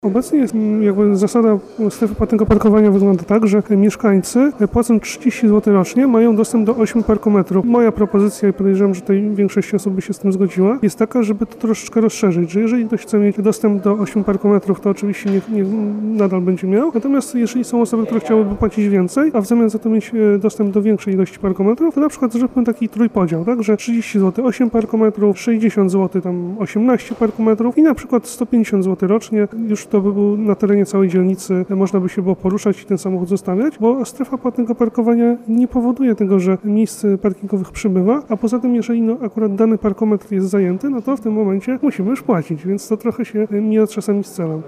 Wypowiedz radnego Ernesta Kobylińskiego z PiSu: